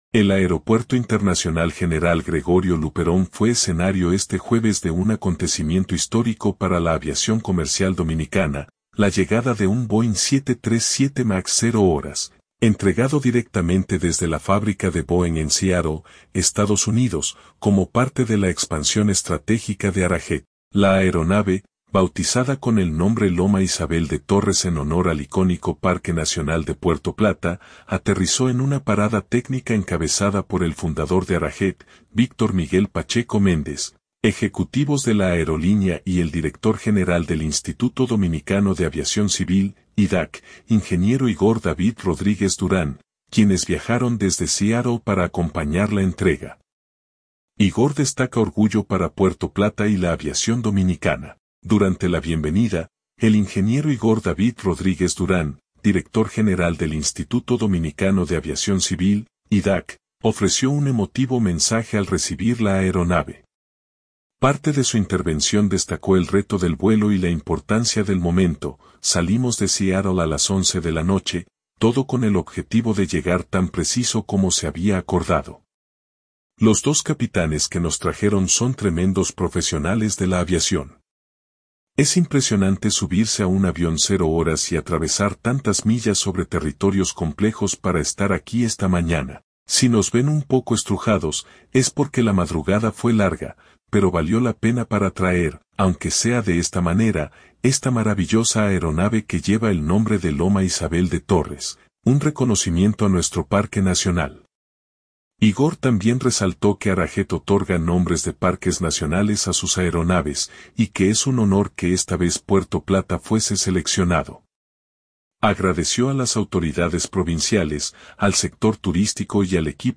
Durante la bienvenida, el ingeniero Igor David Rodríguez Durán, director general del Instituto Dominicano de Aviación Civil (IDAC), ofreció un emotivo mensaje al recibir la aeronave.